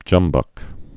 (jŭmbŭk)